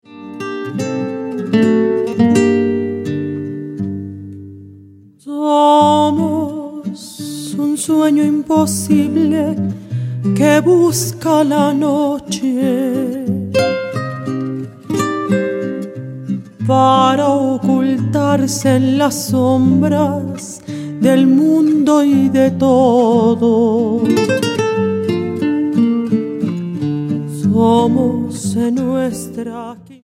mezzosoprano
guitarras